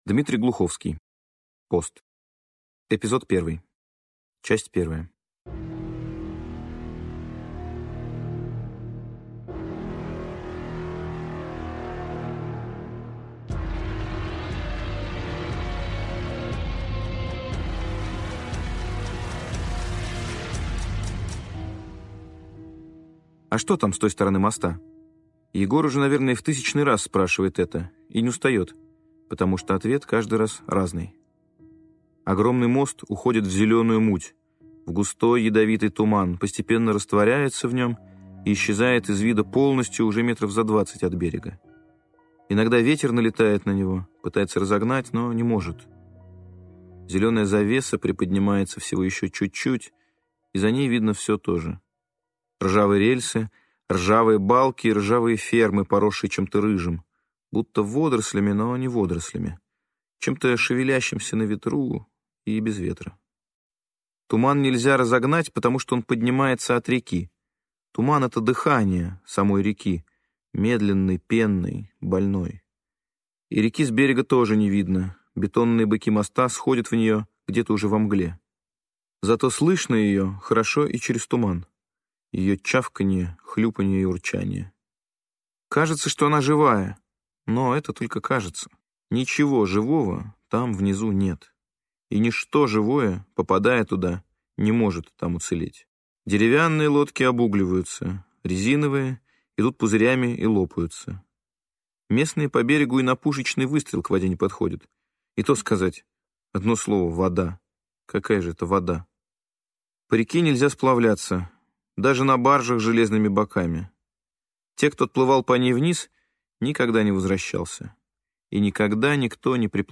Аудиокнига «ПОСТ» в интернет-магазине КнигоПоиск ✅ в аудиоформате ✅ Скачать ПОСТ в mp3 или слушать онлайн